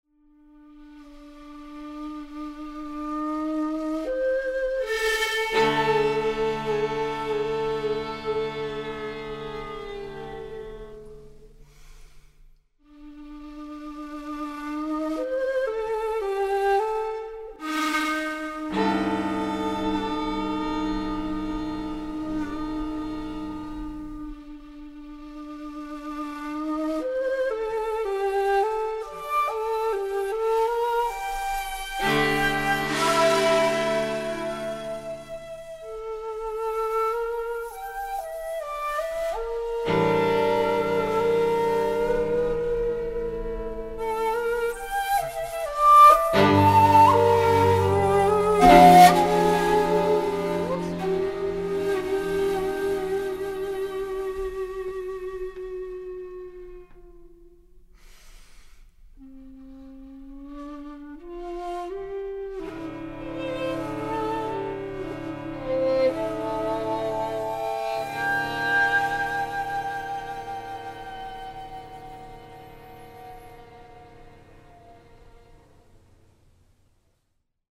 shakuhachi, violin, violoncello 尺八、ヴァイオリン、チェロ